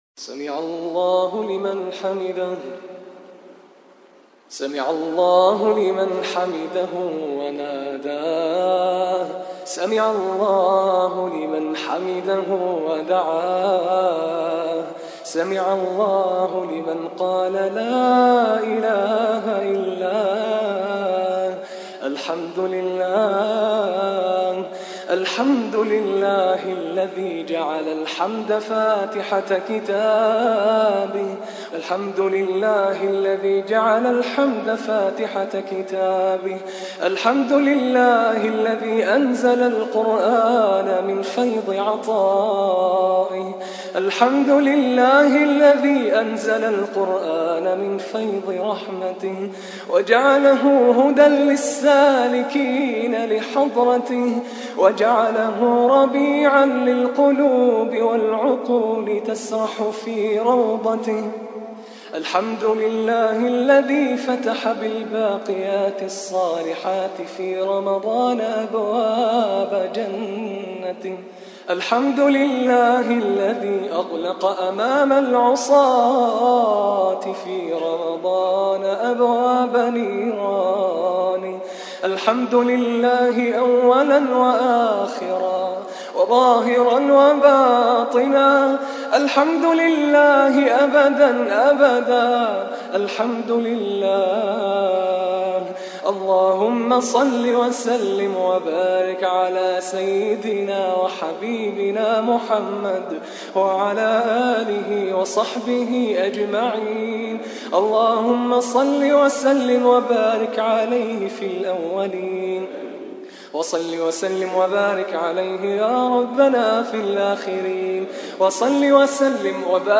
الدعاء
212 الدعاء أدعية وأذكار القارئ